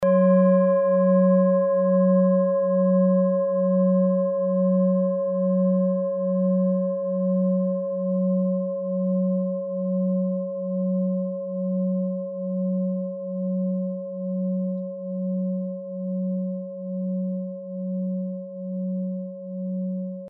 Klangschalen-Typ: Bengalen
Klangschale Nr.1
Gewicht = 1320g
Durchmesser = 22,7cm
(Aufgenommen mit dem Filzklöppel/Gummischlegel)
klangschale-set-1-1.mp3